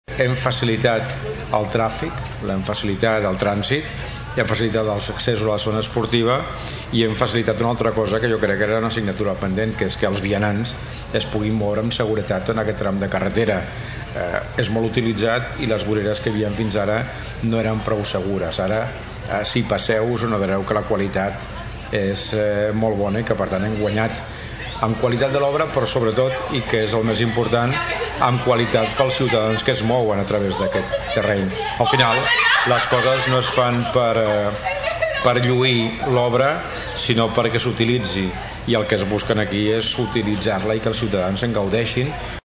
L’alcalde de Tordera, Joan Carles Garcia destacava que l’ obra donarà un millor accés als vianants tant a Sant Pere com a Tordera.
alcalde-bv-5121.mp3